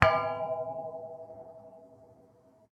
Rest Stop Metal Pole
bonk clang hit impact metal metallic percussion pole sound effect free sound royalty free Memes